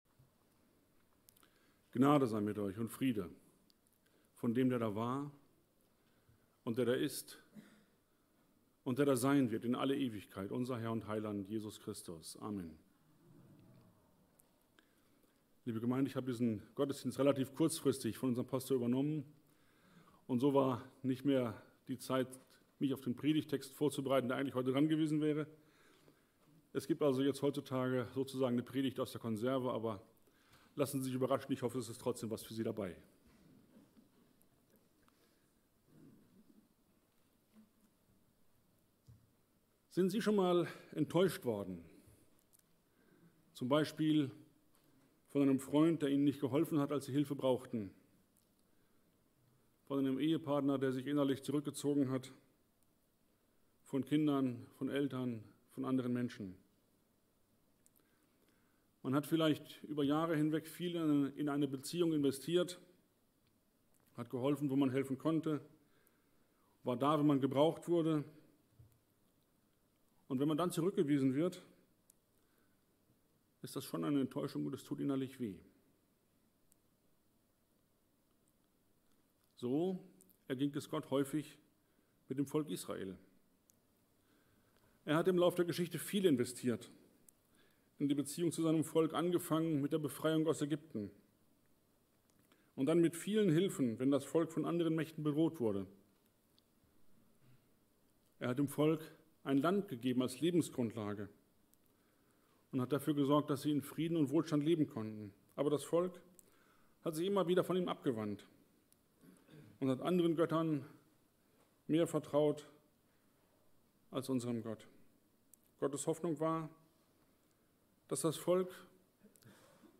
Passage: Hebräer 10,35–36 Dienstart: Gottesdienst « Bespreche Zweifel ehrlich mit Gott!